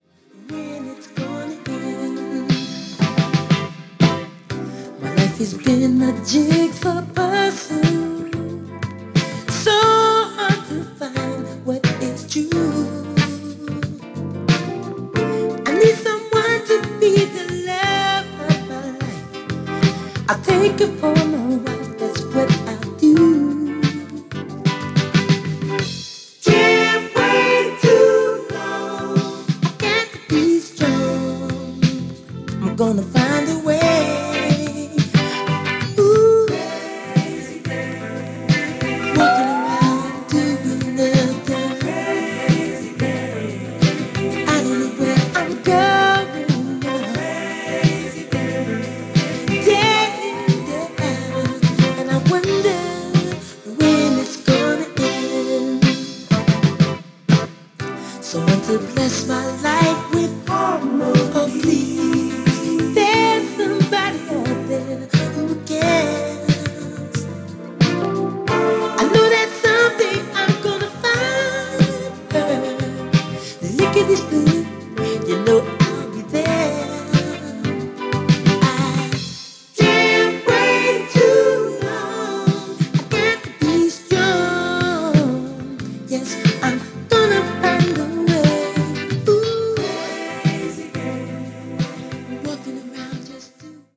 Funk and Soul group